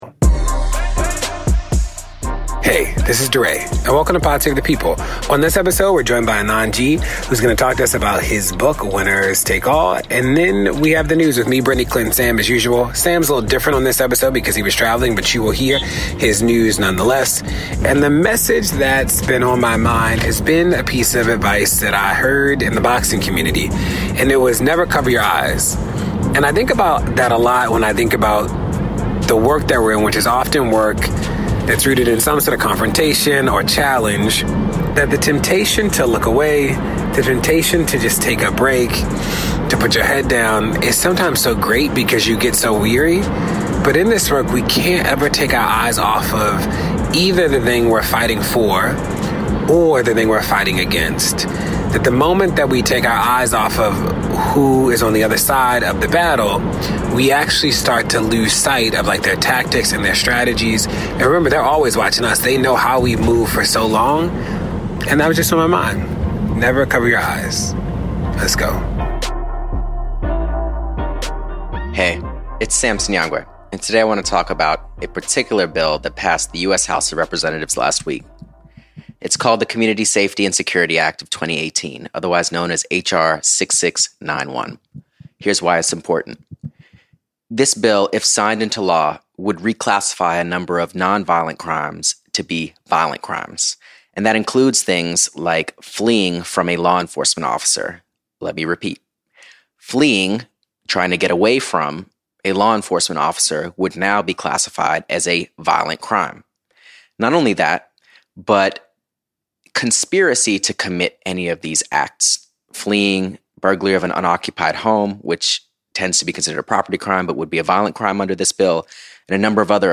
Anand Giridharadas joins DeRay in the studio to talk about his new book, Winners Take All.